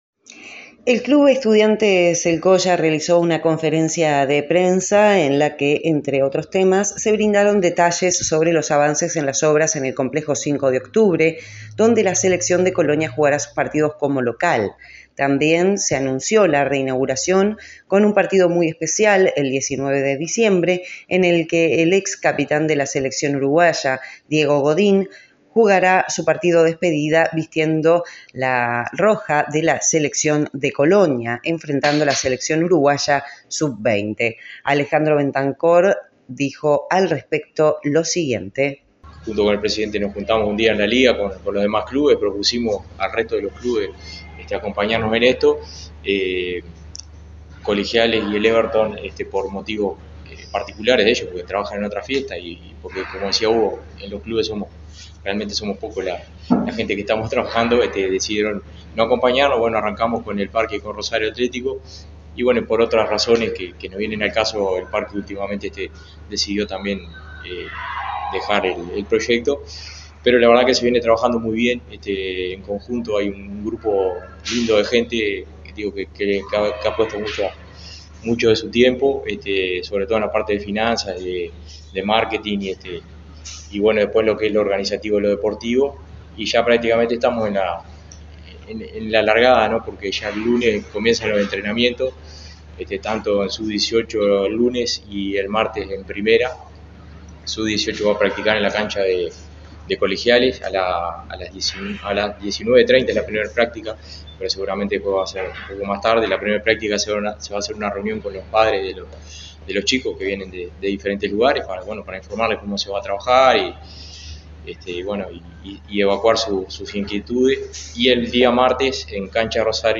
El Club Estudiantes El Colla realizó una conferencia de prensa